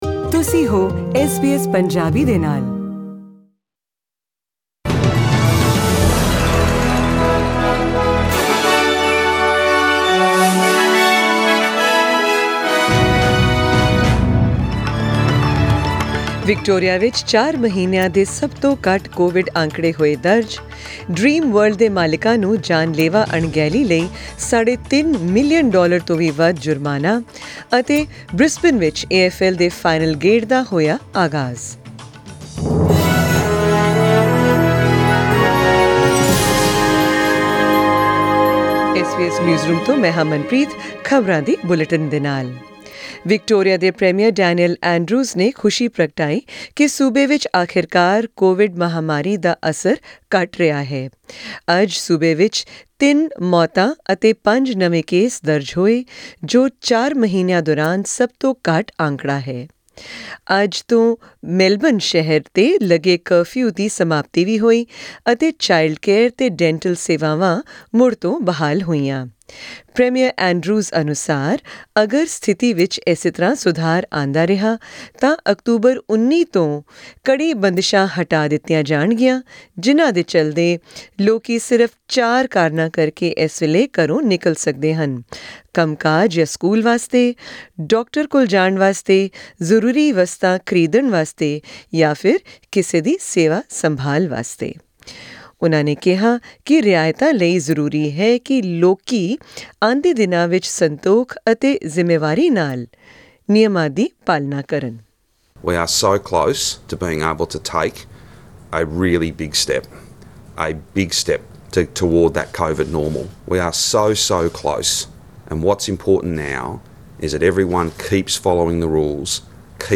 In tonight's SBS Punjabi news bulletin, hear more about Victoria being well ahead of its reopening schedule, the $3.6 million fine to Dreamworld's parent company and the launch of AFL finals week in Brisbane.